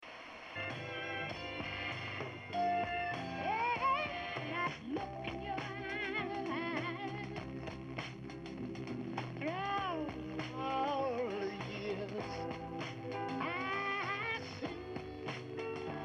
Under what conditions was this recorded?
Remember this was a distant weak signal you'd normally never listen to because the sound is a bit fuzzy with all the antennas tested. Powered 21-23 (varied from test to test) Good with no variance when I walked around the bike antenna_test_powered_reception.mp3